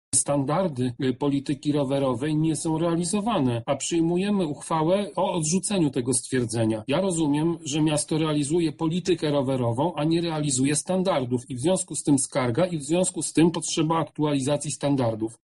• mówi radny Tomasz Pitucha.